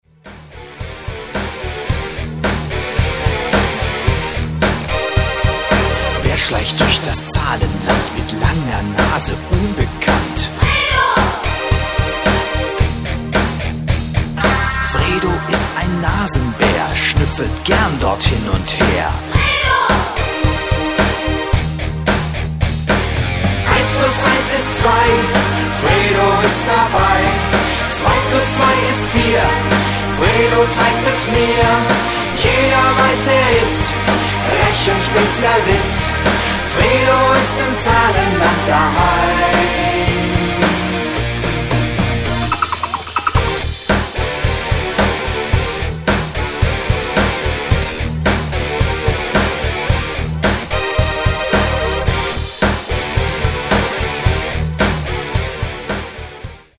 (vocal und playback)